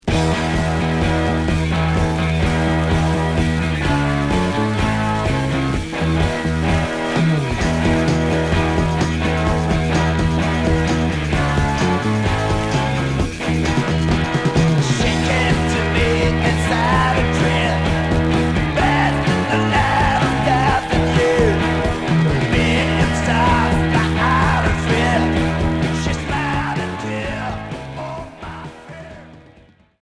Heavy rock 9ème 45t retour à l'accueil